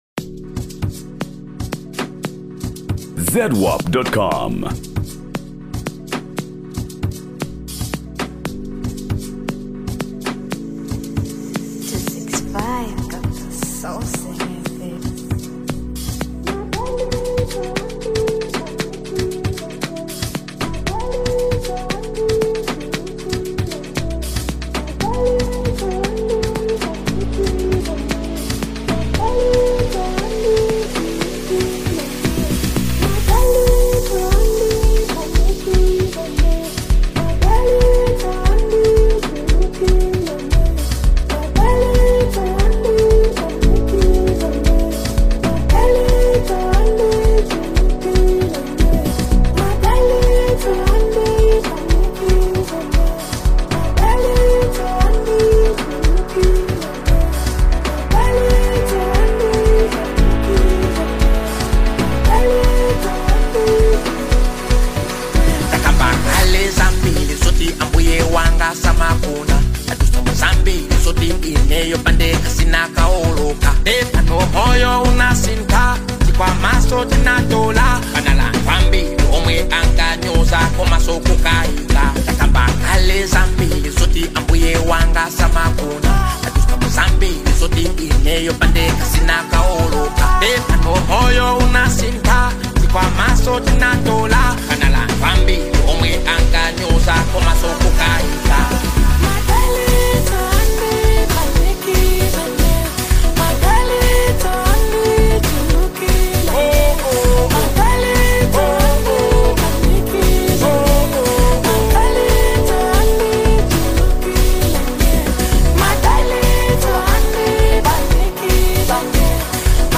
Latest Zambia Afro-Beats Single (2026)
Genre: Afro-Beats